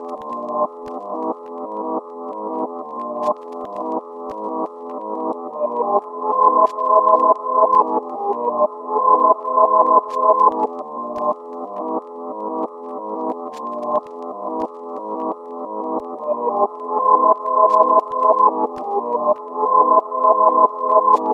Tag: 90 bpm Trap Loops Piano Loops 3.59 MB wav Key : E FL Studio